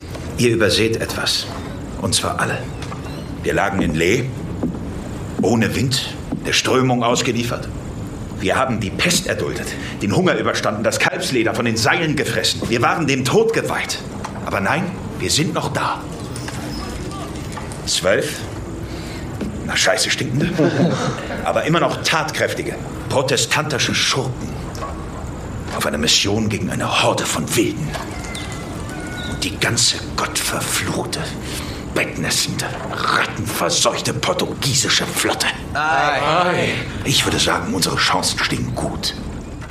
Torben Liebrecht (Berlin) spricht Cosmo Jarvis TORBEN LIEBRECHT steht seit seinem 15.